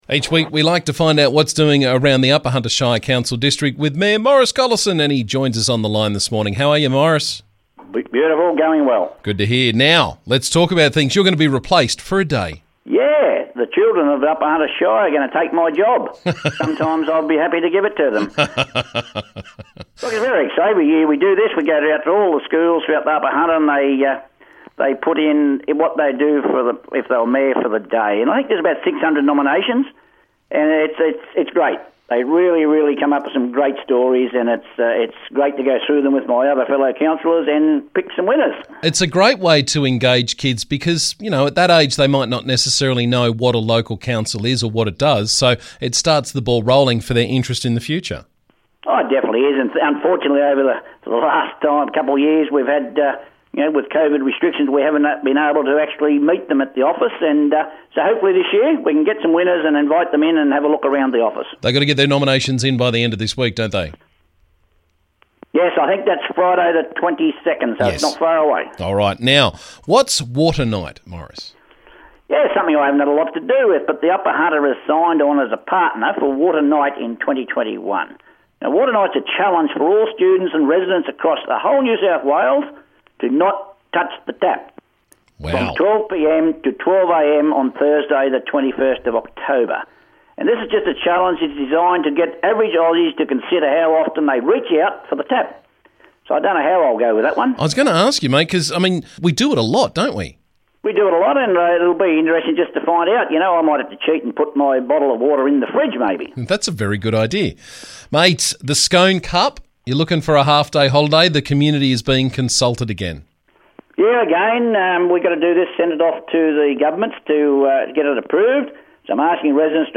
Upper Hunter Shire Council Mayor Maurice Collison caught us up with the latest from around the district.